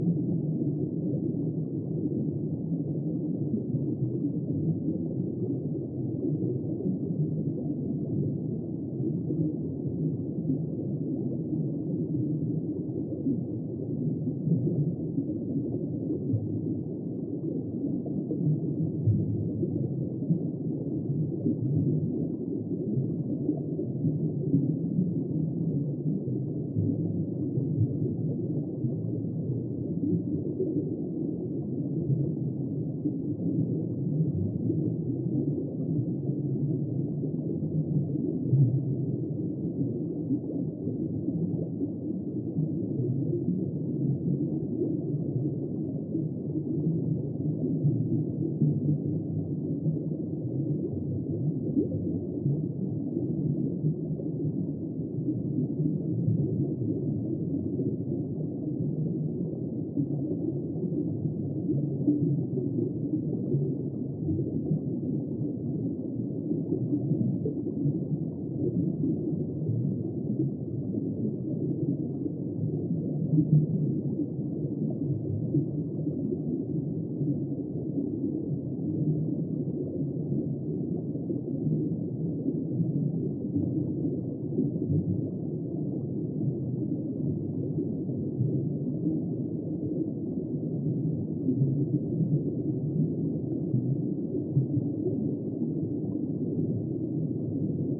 Звуки подводного мира
Атмосферные звуки морских глубин (зациклено)